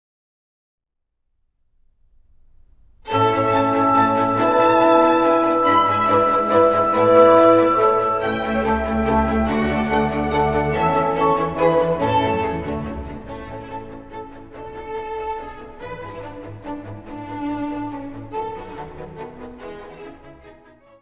Notes:  Gravado no Concert Hall, New Broadcasting House, Manchester, de 26 a 27 de Outubro, 1993; Disponível na Biblioteca Municipal Orlando Ribeiro - Serviço de Fonoteca
Music Category/Genre:  Classical Music
Allegro.